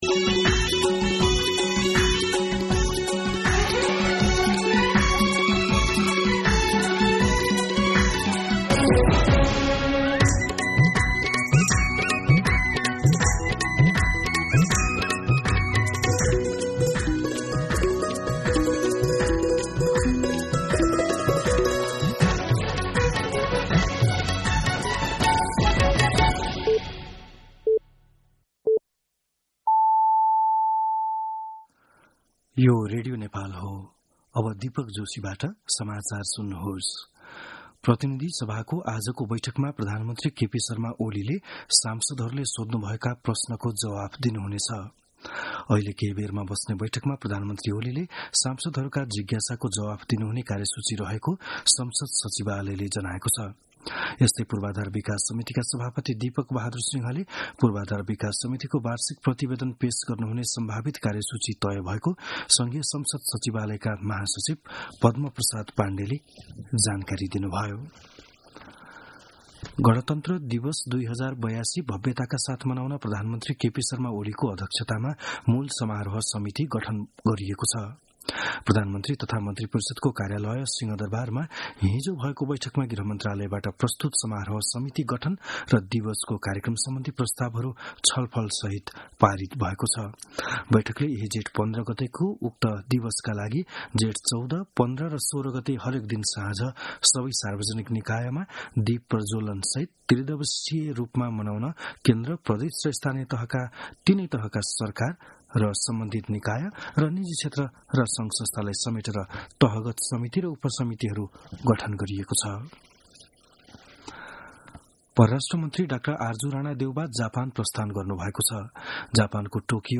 बिहान ११ बजेको नेपाली समाचार : ६ जेठ , २०८२